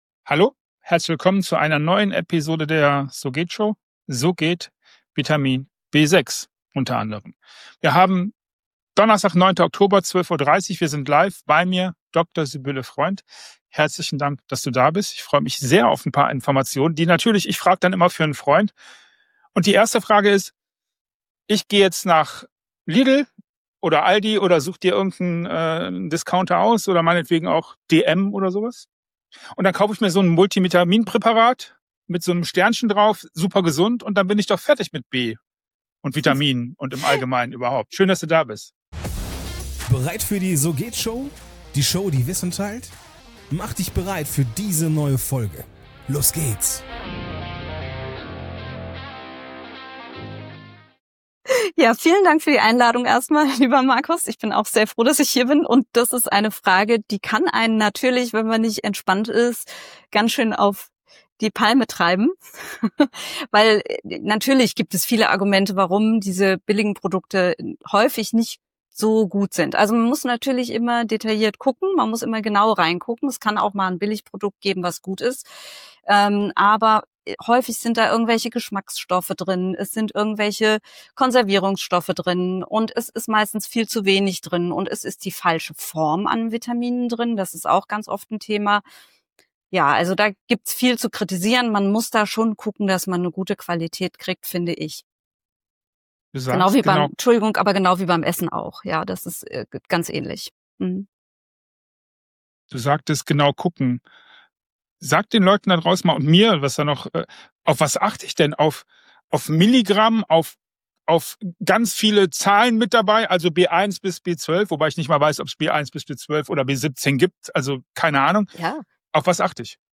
Die „So-Geht-Show“ verfolgt eine andere Idee: 30 Minuten ungefilterte Antworten und viele viele Fragen.
Keine Nachbearbeitung, keine zweiten Versuche – was gesagt wird, bleibt.